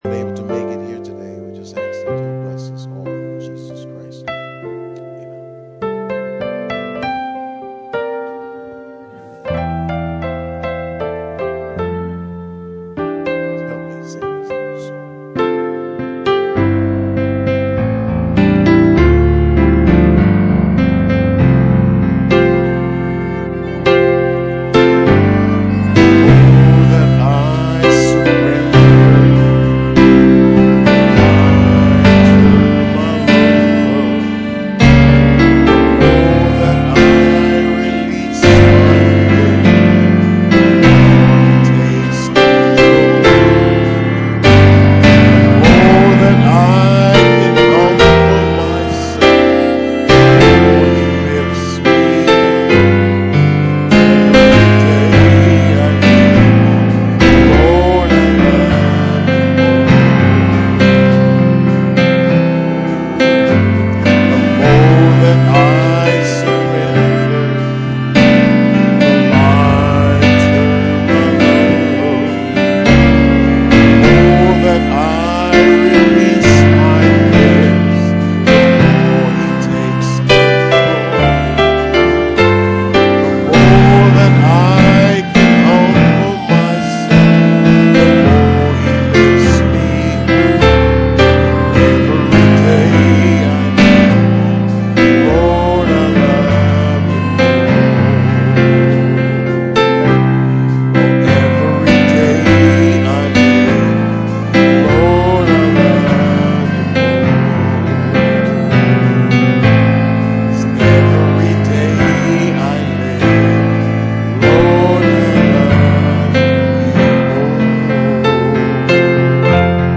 Series: Bible Study